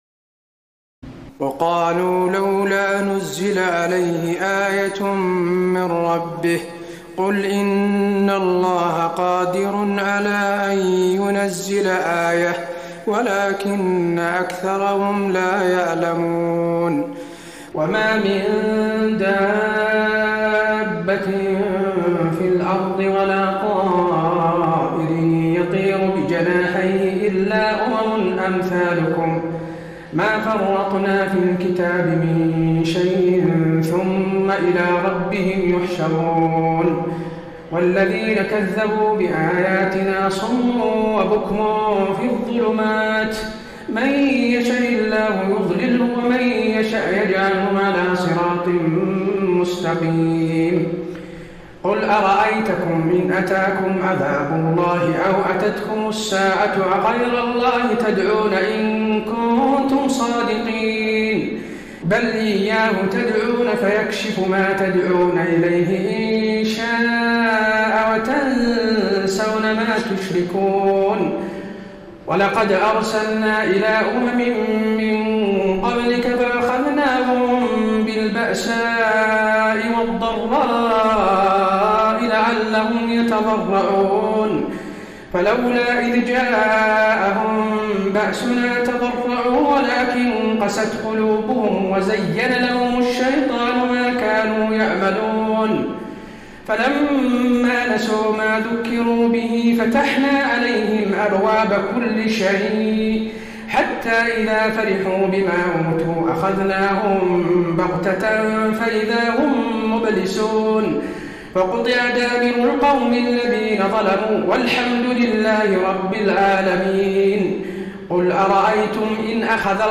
تراويح الليلة السابعة رمضان 1433هـ من سورة الأنعام (37-111) Taraweeh 7 st night Ramadan 1433H from Surah Al-An’aam > تراويح الحرم النبوي عام 1433 🕌 > التراويح - تلاوات الحرمين